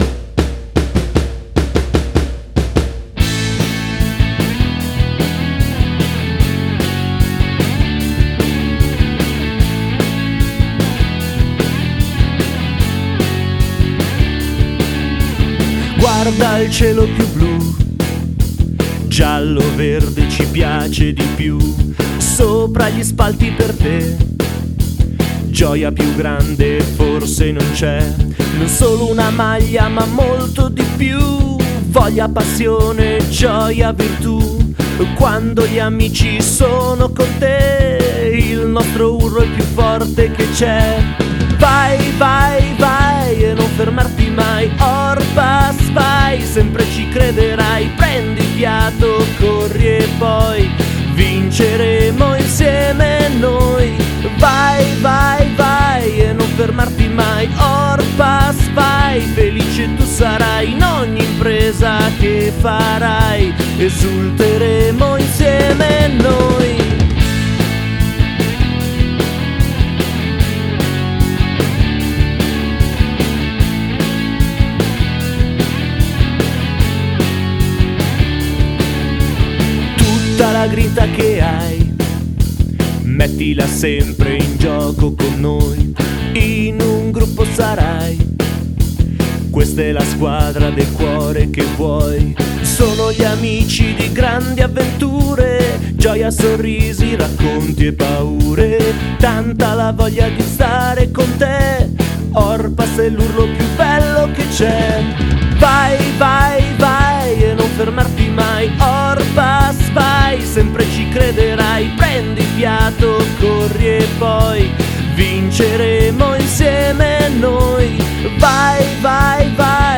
E infine....l'inno ufficiale dell'Orpas in una versione sottotitolata per imparare bene le parole e cantarlo tutti insieme fin dalla prossima partita!!